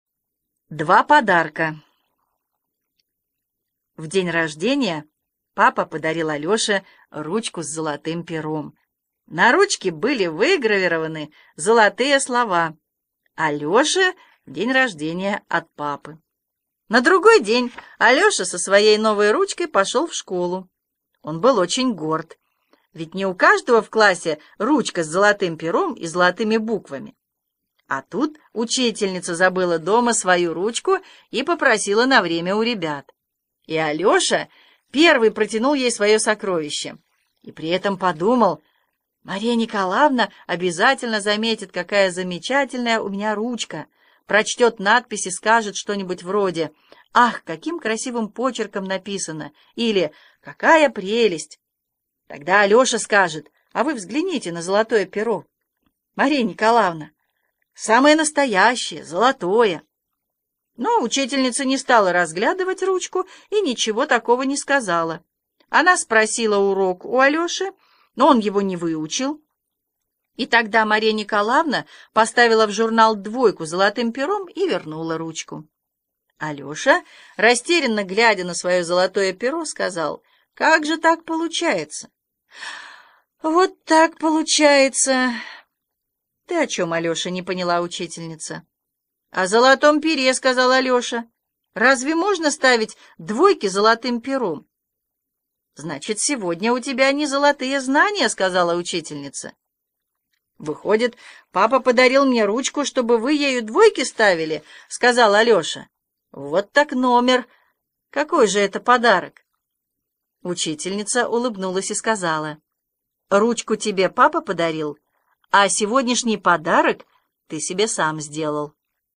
Аудиорассказ «Два подарка»